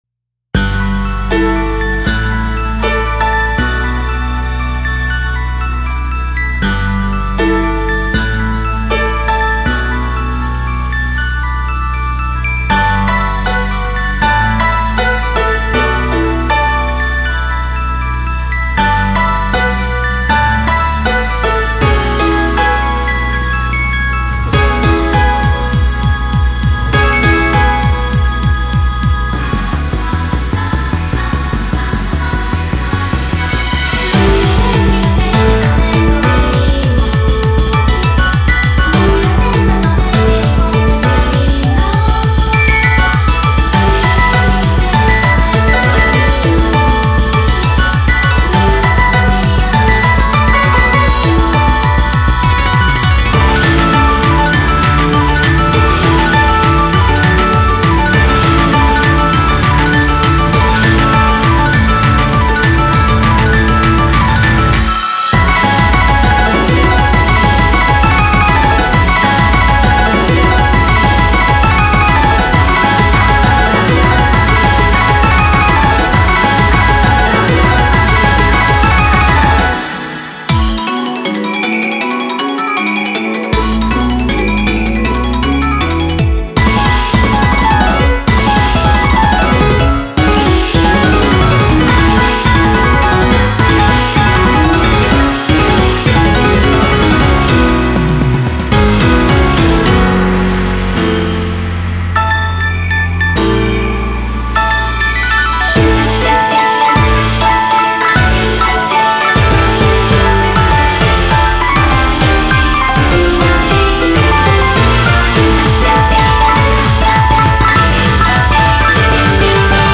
BPM79-200
Audio QualityPerfect (High Quality)
Genre: FANTASY RENAISSANCE